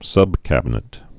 (sŭbkăbə-nĭt)